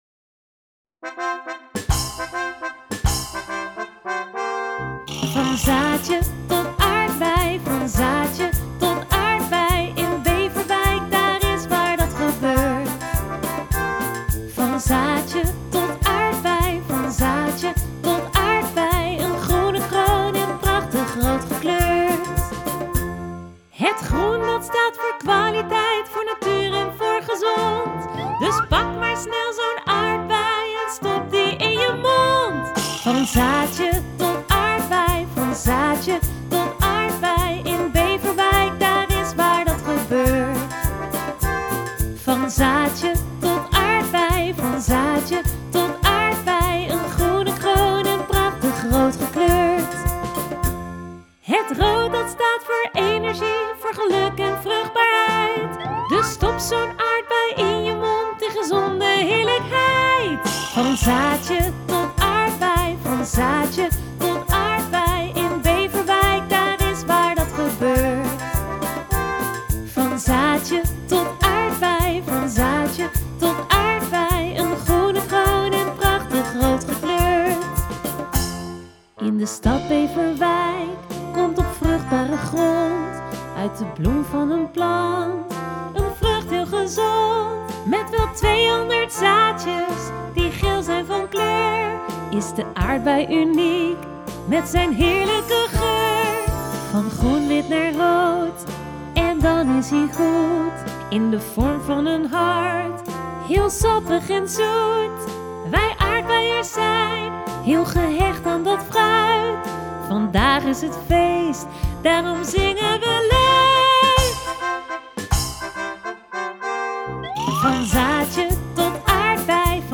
Van Zaadje tot Aardbei: vrolijk kinderlied ter ere van 750 jaar marktrecht